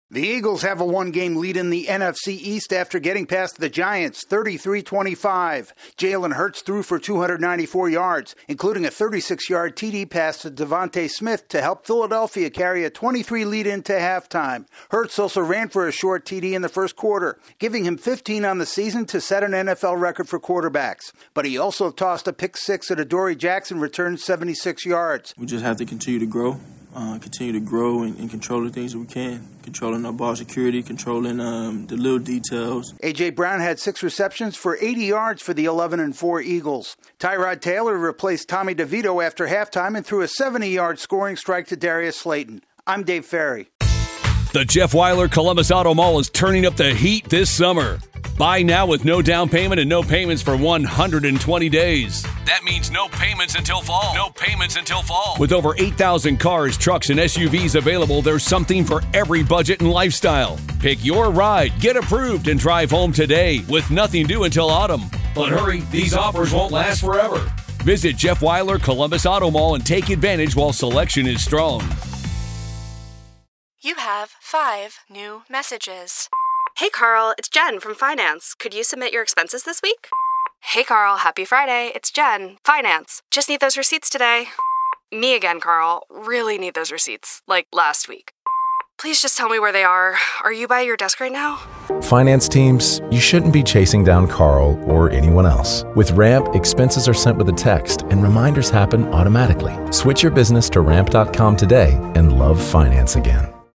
The Eagles pick up a much-needed win over the Giants. AP correspondent